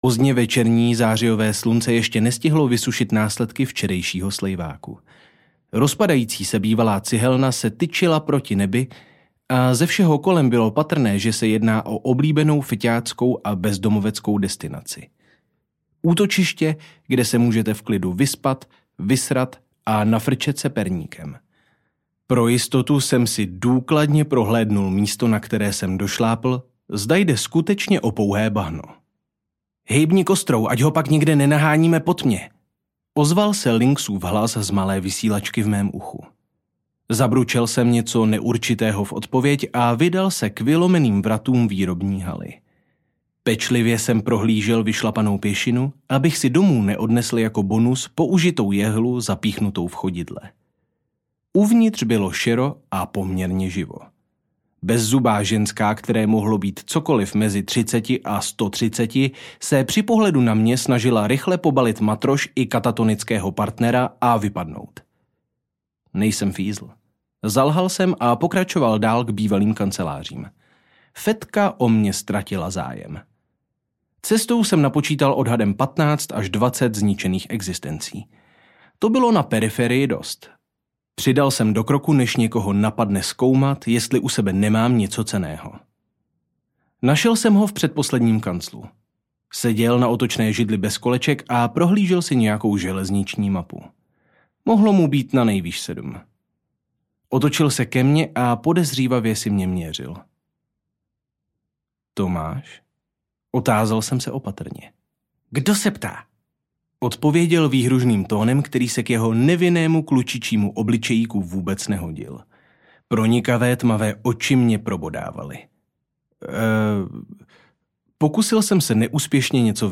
Tanec papírových draků 2: Hon audiokniha
Ukázka z knihy